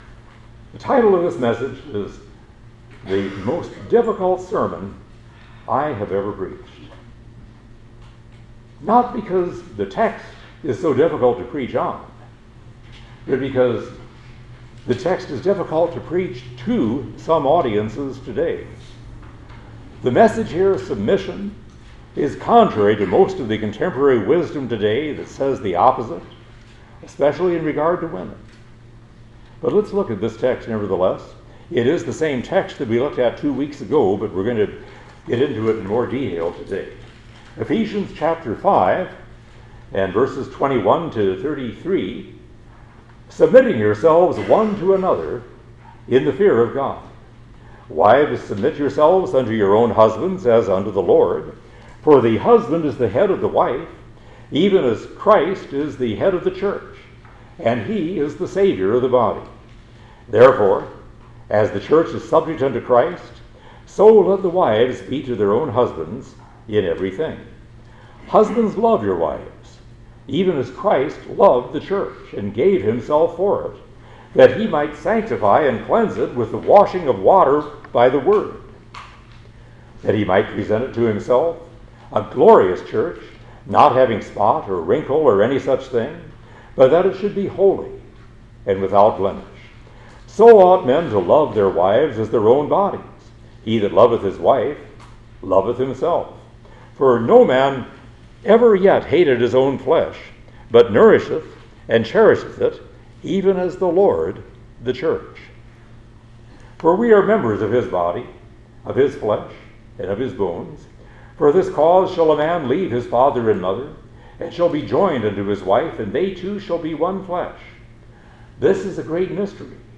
The-Most-Difficult-Sermon-I-Have-Ever-Preached.mp3